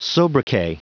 Prononciation du mot sobriquet en anglais (fichier audio)
Prononciation du mot : sobriquet